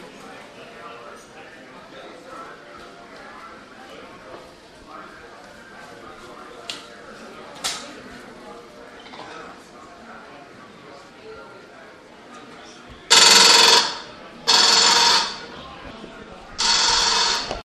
描述：一个可能被称为"Rosy the Riveter"的显示屏，在任何提及性别或种族在美国成为非法之前，用DS40录制，因为左边的麦克风神秘地停止工作，在Wavosaur中作为单声道录音被挽救。
Tag: 场记录 单声道 公路旅行 夏天 旅游 度假 华盛顿DC